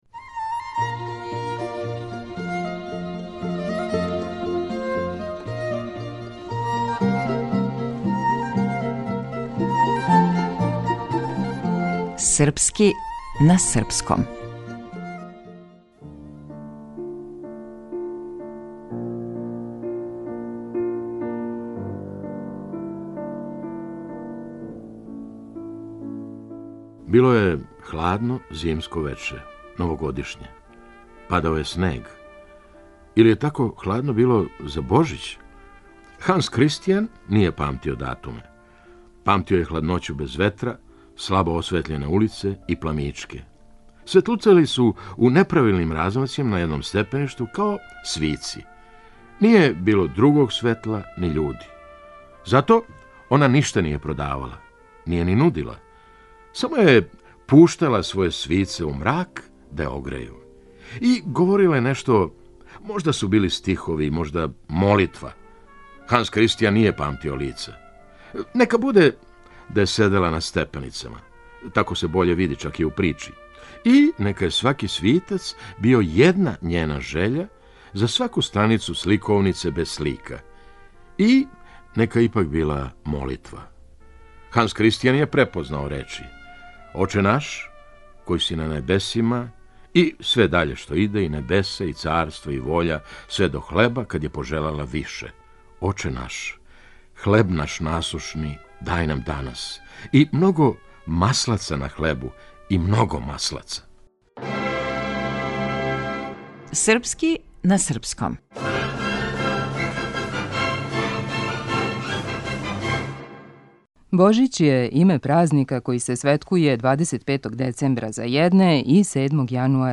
Глумац - Феђа Стојановић